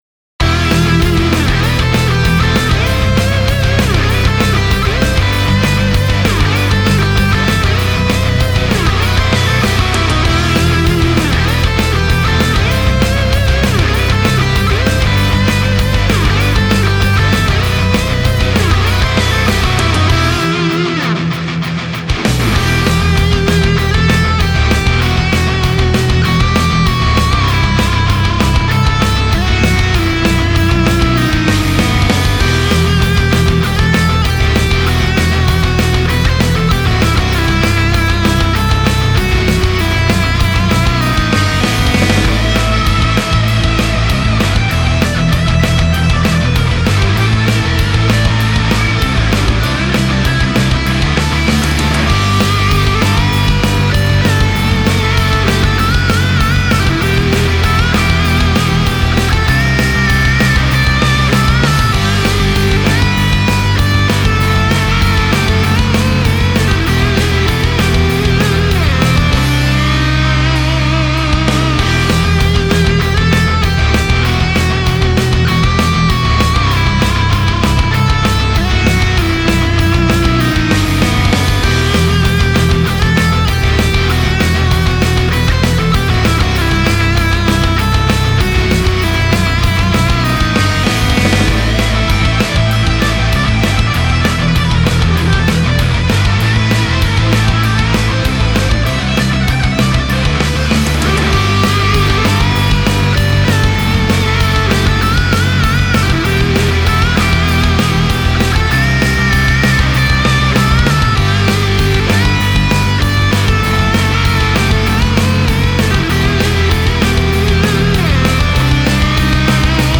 ROCK
Fat Rock